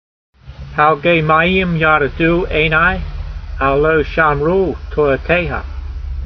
Sound (Psalm 119:136) Transliteration: palgay- mai 'yeem yar(d)e' doo ay n ai , al lo- sham r oo tora tey ha Vocabulary Guide: Streams of tears run down my eyes because they do not keep your law .